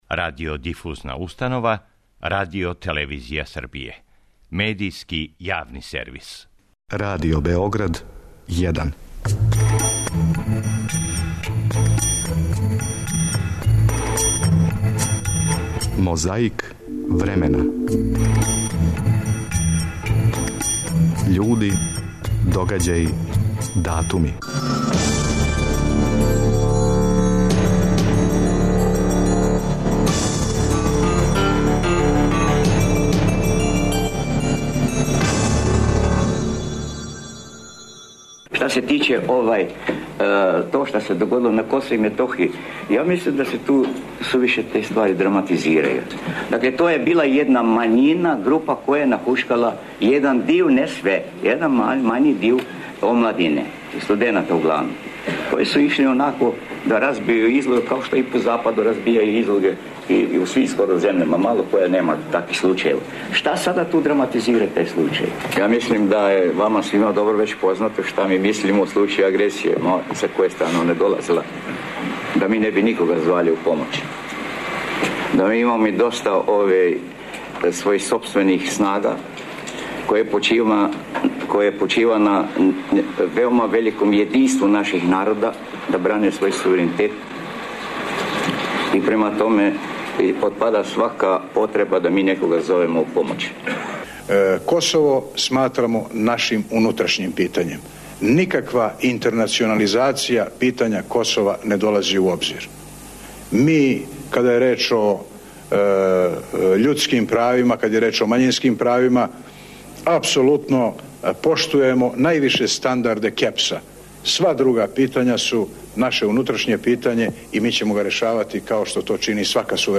Иста тема, различити говорници.